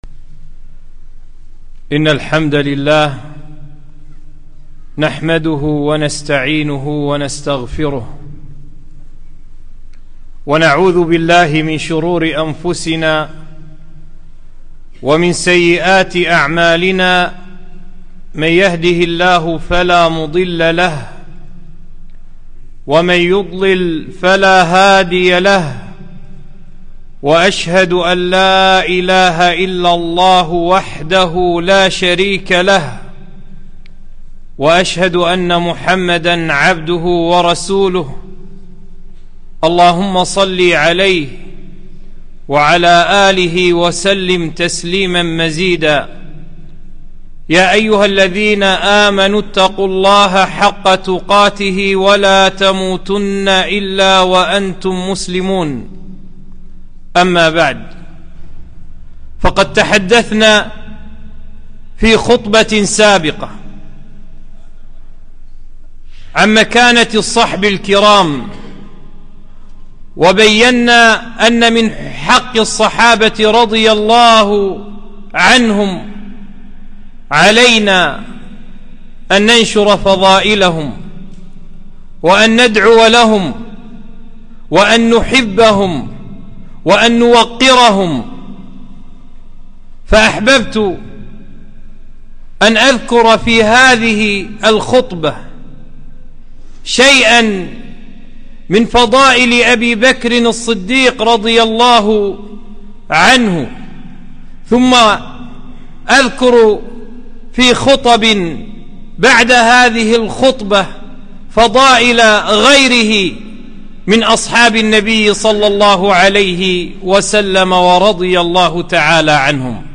خطبة - فضائل أبي بكر الصديق - رضي الله تعالى عنه